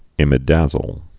(ĭmĭ-dăzōl)